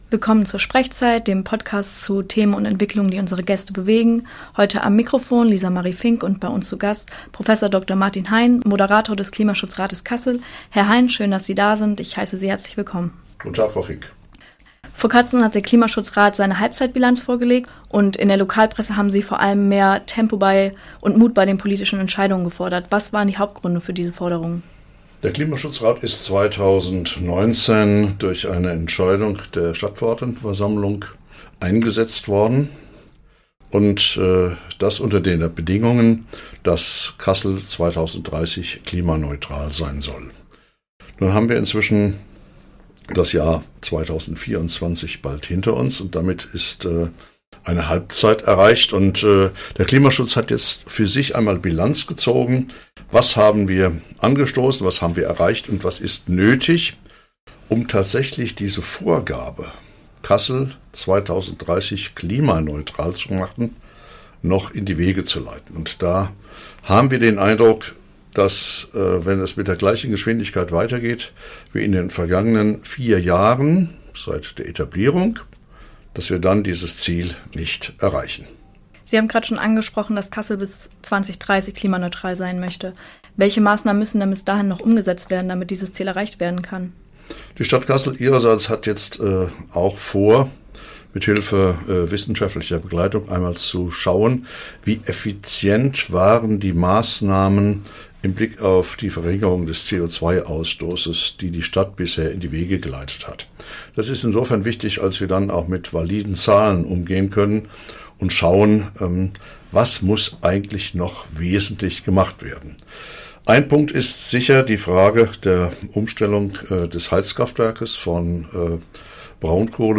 Im Sprechzeit-Podcast erläuterte Martin Hein die Gründe seiner Forderung und sprach über die Zukunft des Klimaschutzes in Kassel. Dieser SprechZeit-Podcast wurde am 1.11.2024 beim Freien Radio Kassel aufgezeichnet.